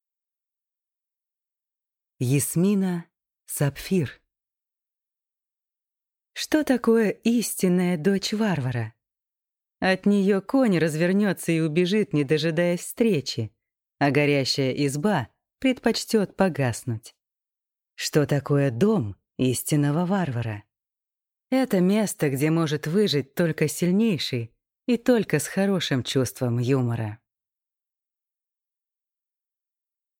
Аудиокнига Истинная дочь варвара | Библиотека аудиокниг